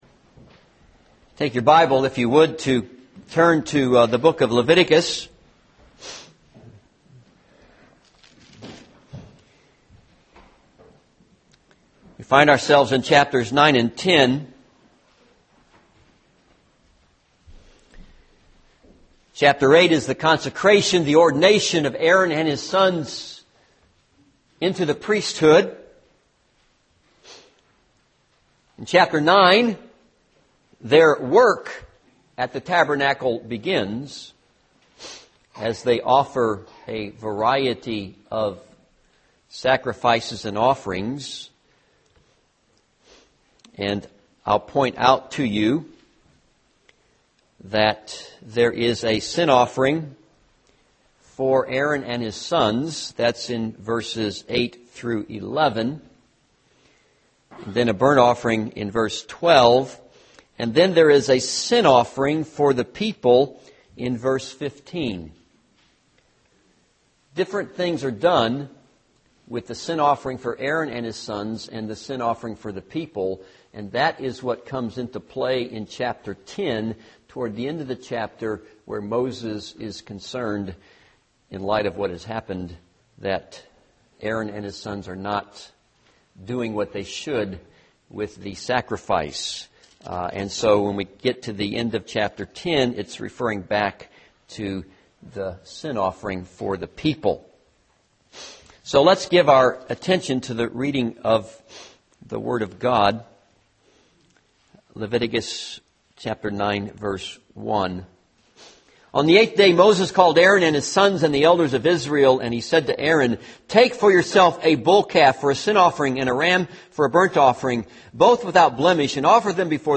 This sermon is based on Leviticus 9 and Leviticus 10.